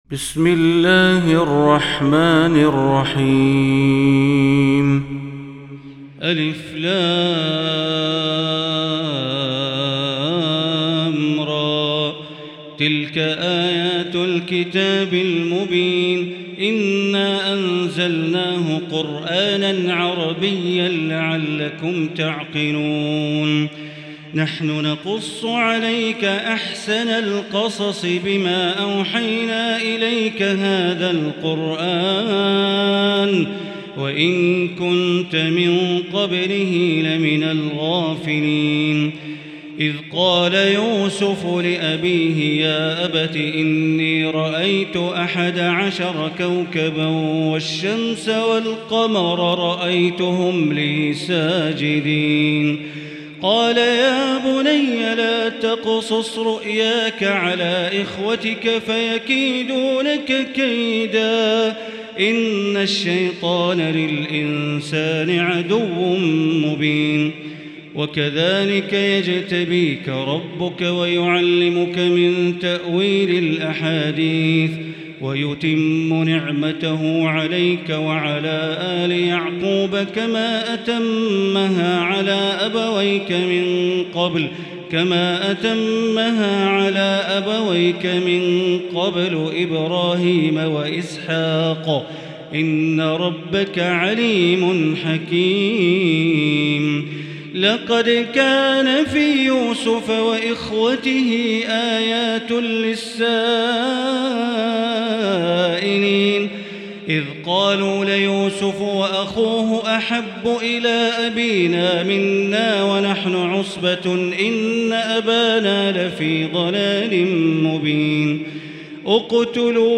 المكان: المسجد الحرام الشيخ: معالي الشيخ أ.د. بندر بليلة معالي الشيخ أ.د. بندر بليلة معالي الشيخ أ.د. عبدالرحمن بن عبدالعزيز السديس فضيلة الشيخ عبدالله الجهني يوسف